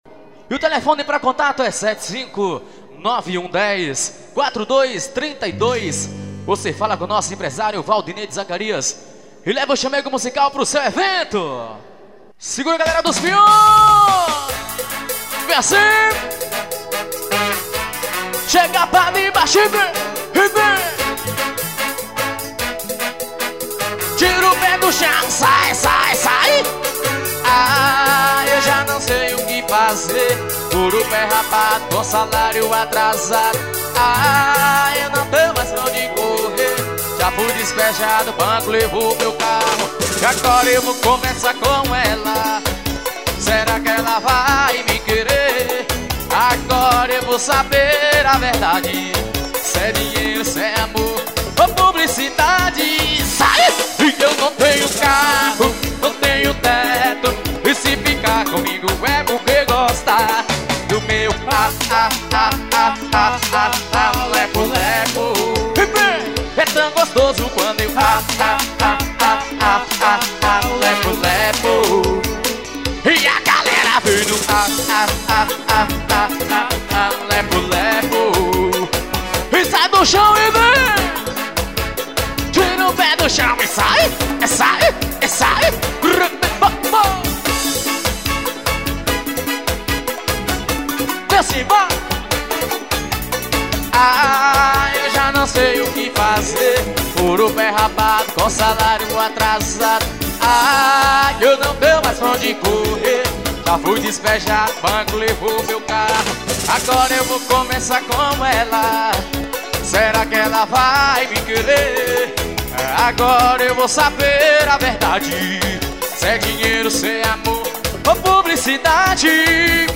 Ao vivo nos piões.